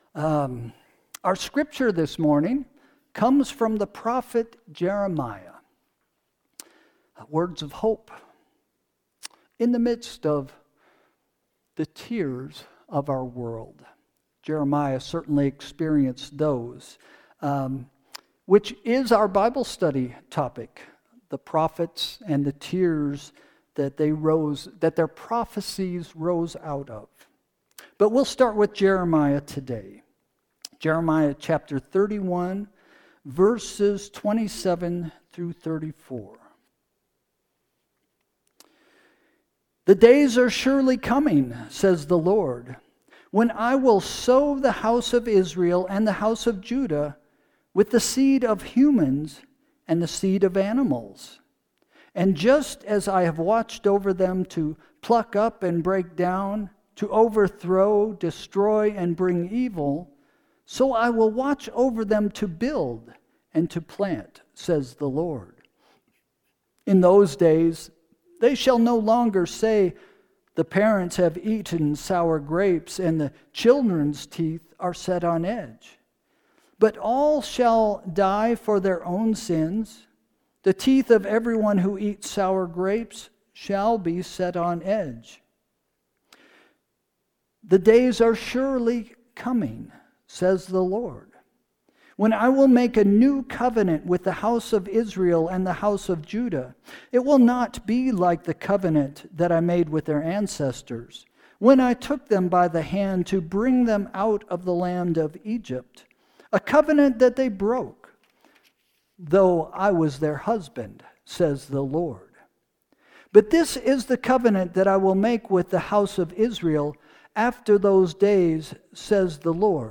Sermon – June 15, 2025 – “Hope is a Choice”